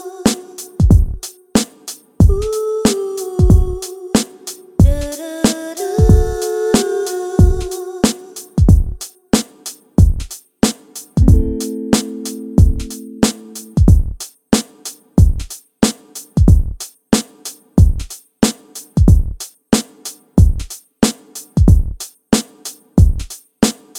no male Backing Vocals R'n'B / Hip Hop 5:07 Buy £1.50